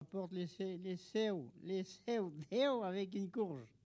Elle provient de Bouin.
Catégorie Locution ( parler, expression, langue,... )